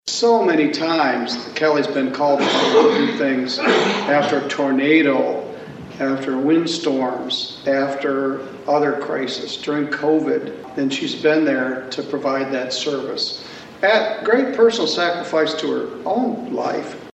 One of the people who spoke during the ceremony was St. Joseph County District Court Judge Jeffrey Middleton.